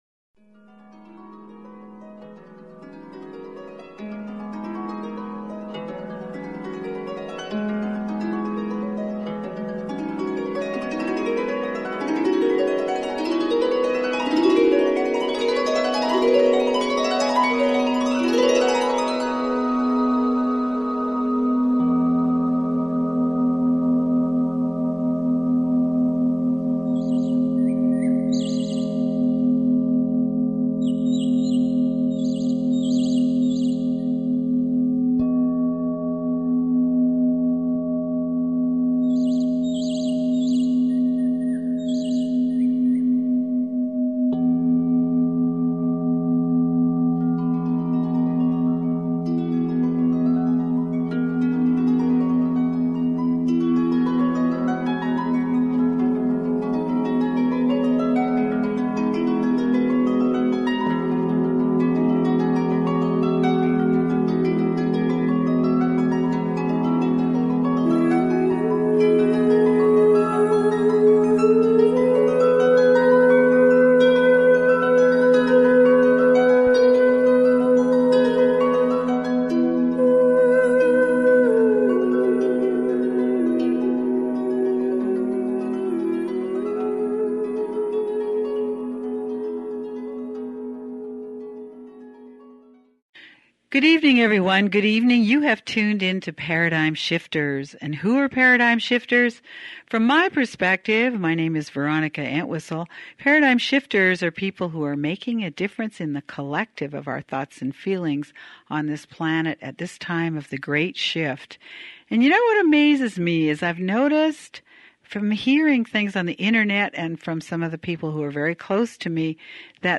Talk Show Episode
We taped this show last year and am delighted to run it again to lift and inspire us all.